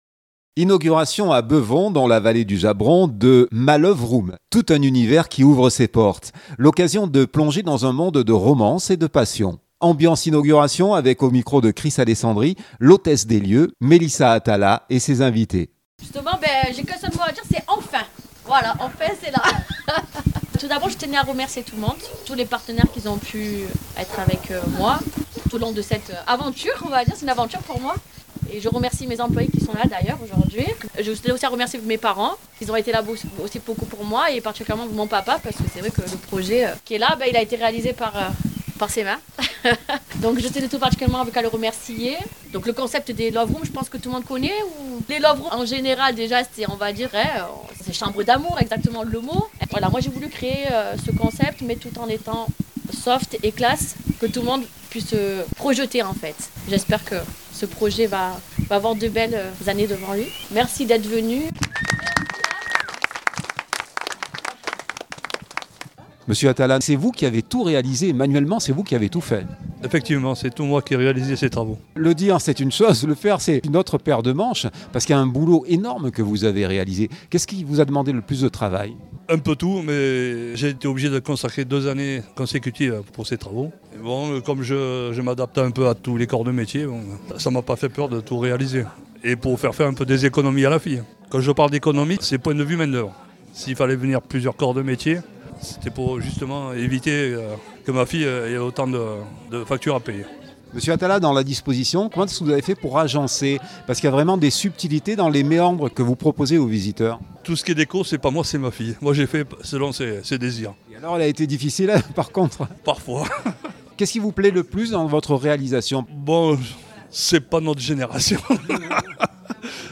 Inauguration à Bevons dans la vallée du Jabron de: « Ma Loveroom », tout un univers qui ouvre ses portes! l’occasion de plonger dans un monde de romance et de passion. Ambiance inauguration